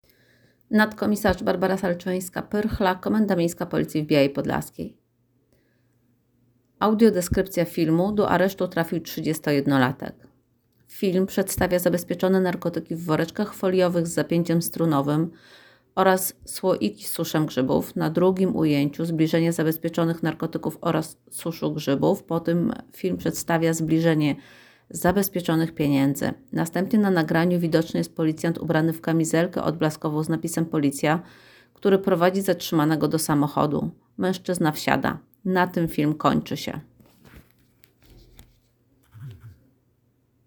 Nagranie audio Audio_deskrypcja_filmu_do_aresztu_trafil_31-latek.m4a
Opis nagrania: Audiodeskrypcja filmu do aresztu trafił 31-latek